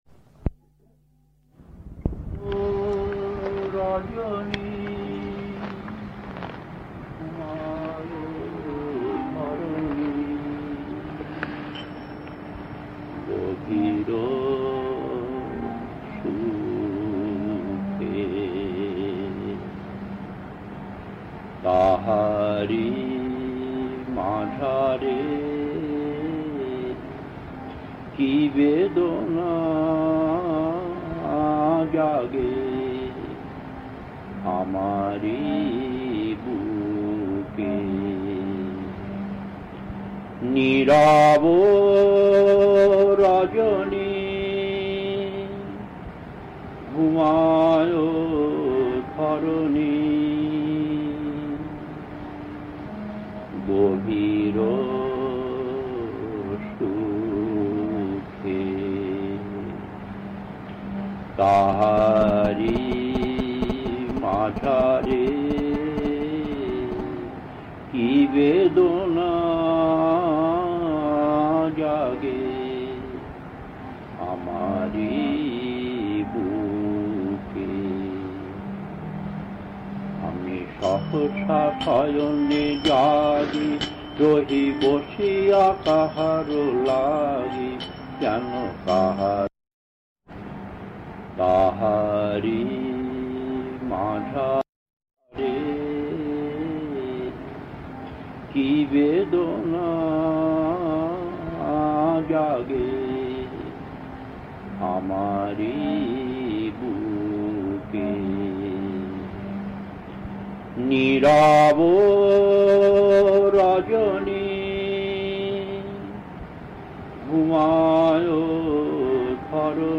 Kirtan B10-2 Pahalgam, 1980, 67 Minutes 1.